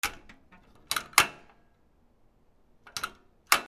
金属カチャカチャ
/ M｜他分類 / L01 ｜小道具 / 金属
『カチャ』